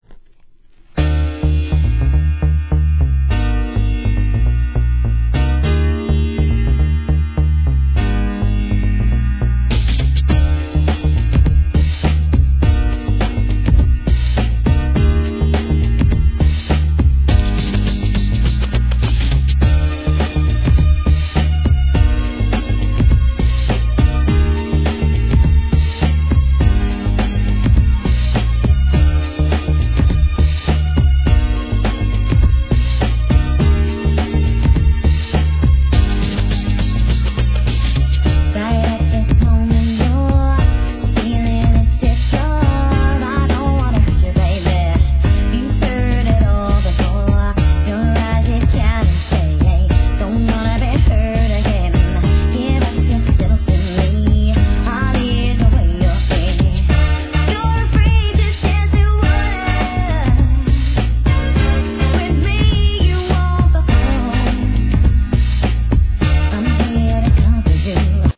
HIP HOP/R&B
ピアノとシンセのメロディーで伸びやかに歌い上げた1993年、グランド・ビート代表作!